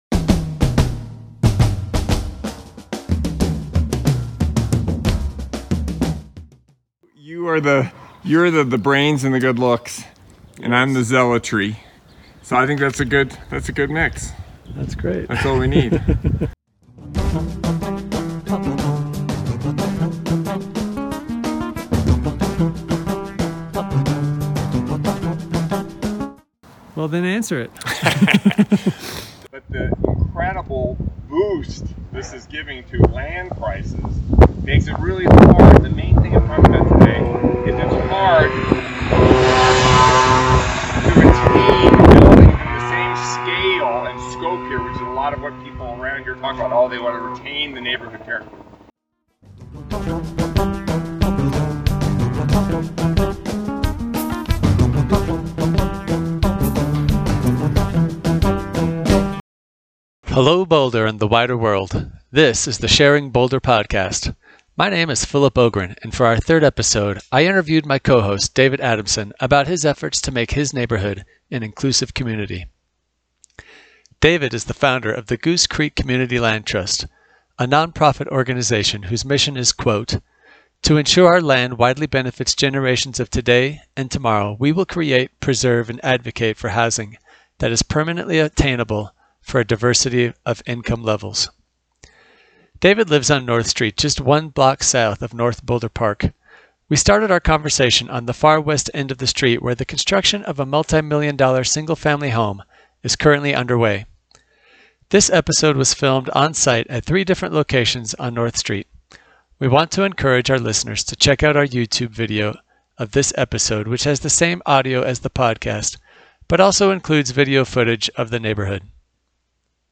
We started our conversation on the far west end of the street where the construction of a multi-million dollar single family home is currently underway.